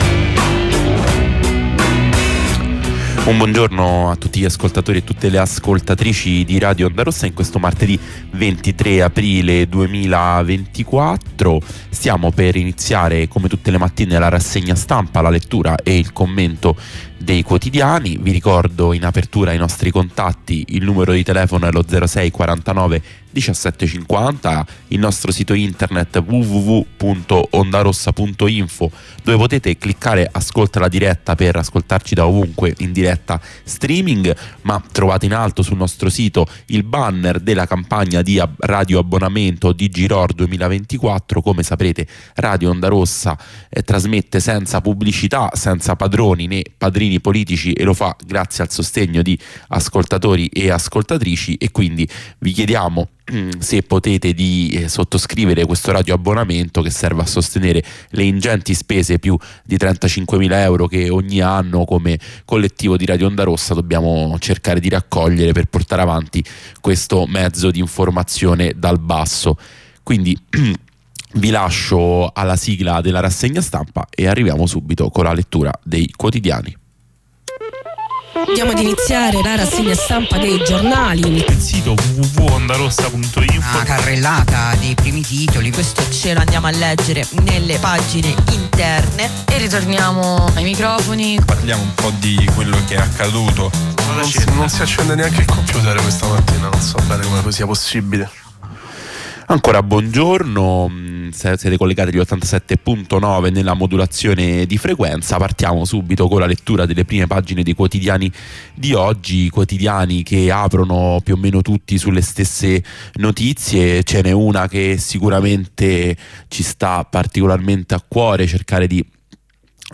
Lettura e commento dei quotidiani. Da lunedì a venerdì alle 8, il sabato alle 9, sugli 87.9 fm di Radio Ondarossa.